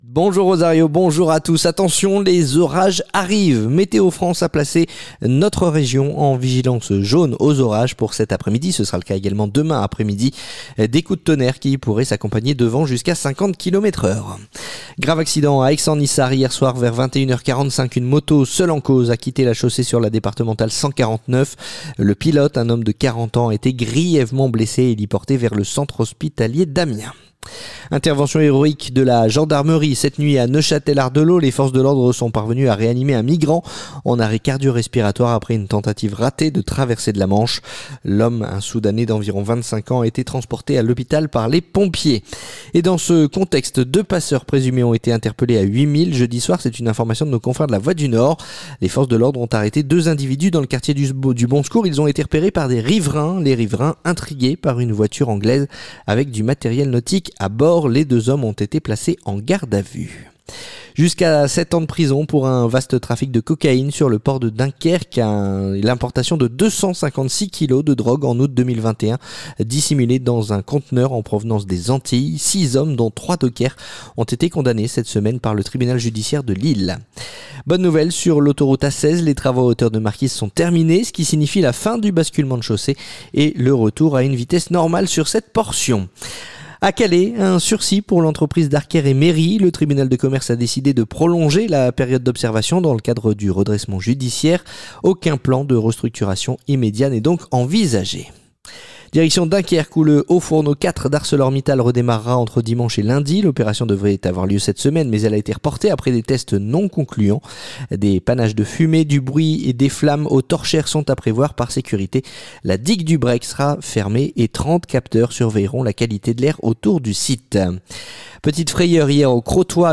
Le journal du samedi 19 juillet